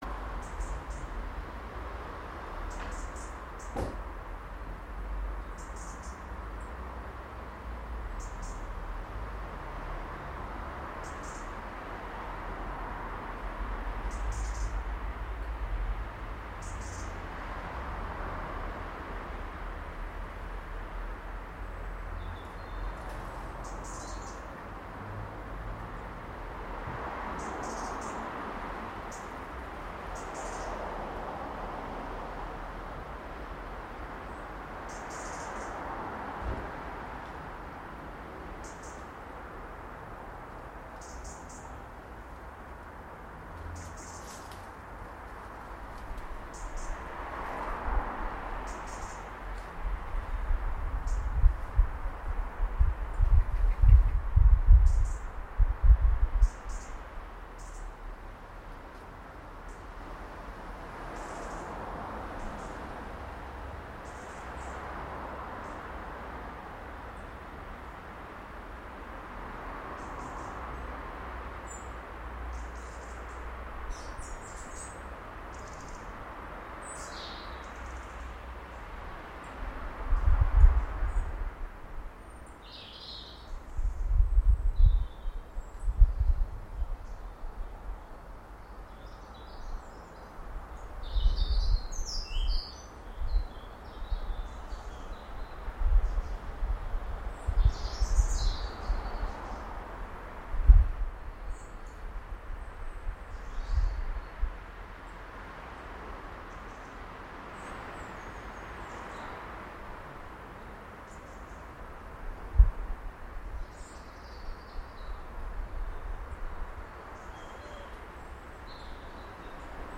6.35 Birdsong 12 October 2012